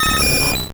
Cri de Roucoups dans Pokémon Or et Argent.